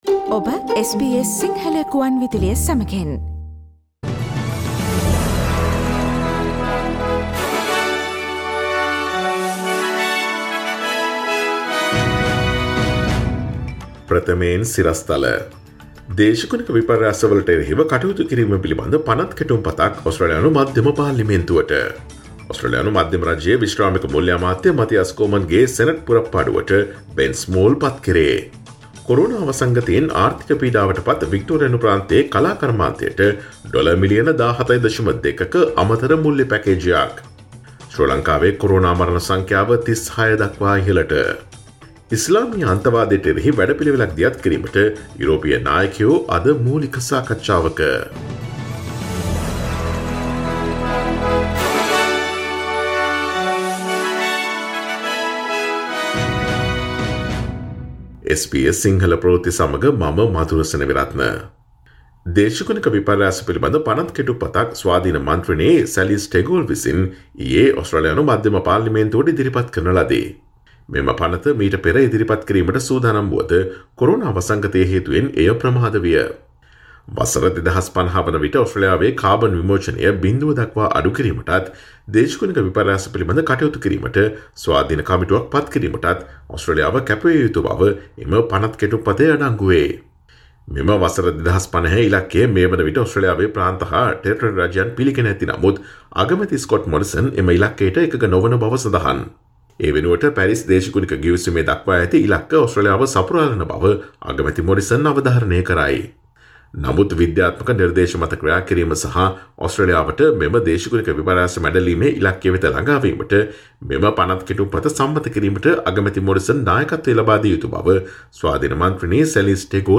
Daily News bulletin of SBS Sinhala Service: Tuesday 10 November 2020